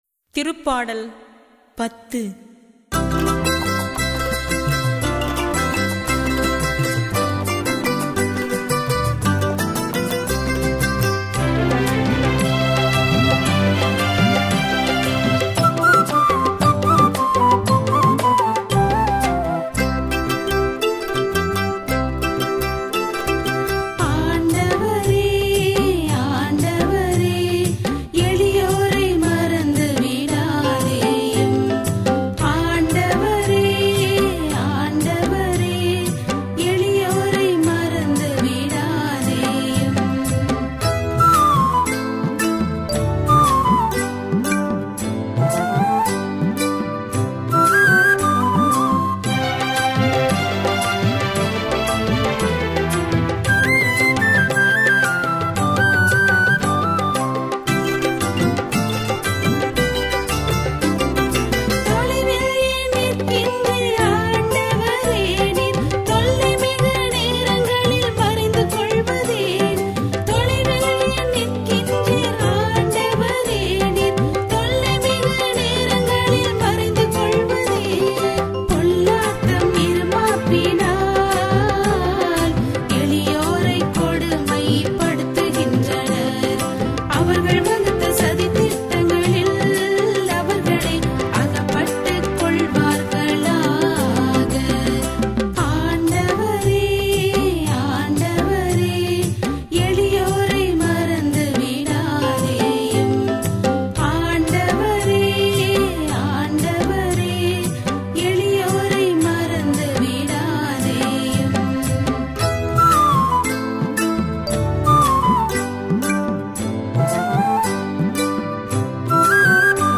பதிலுரைப் பாடல் -